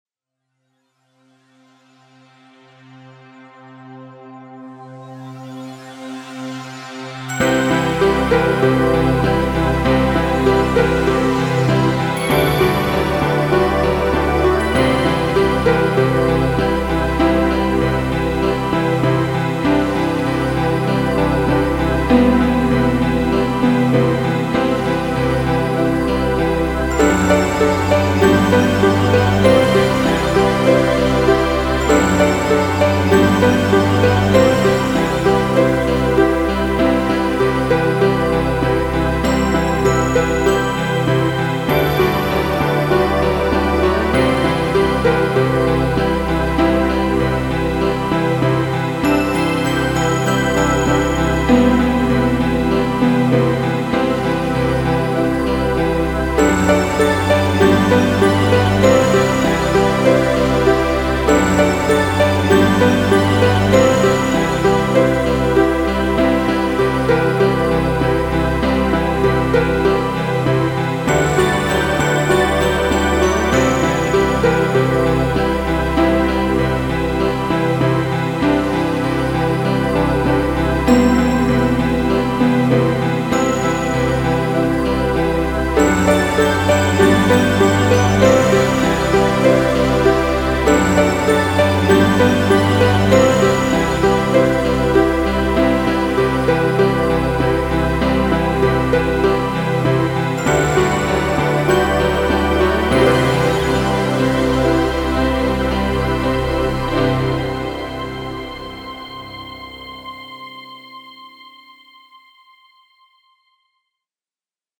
Sintetizadores e Programação